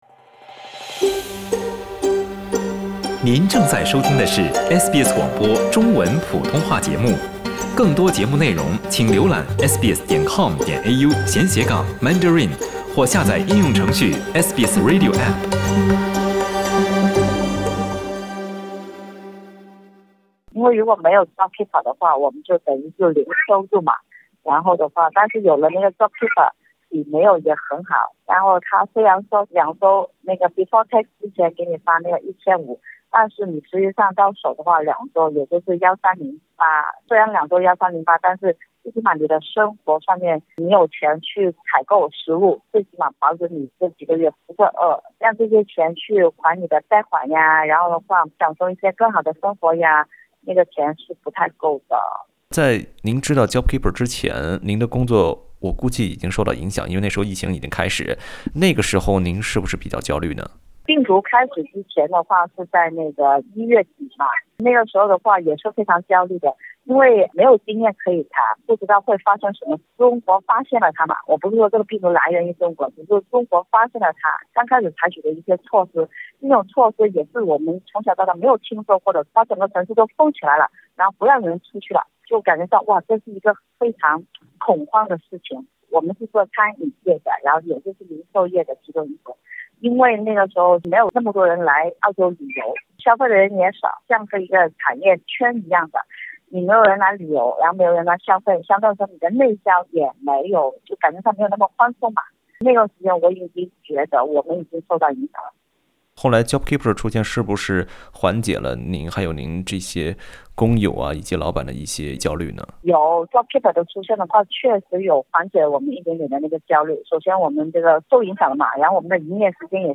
【COVID-19报道】一个餐饮员工的自述：缩衣节食因Jobkeeper或将停发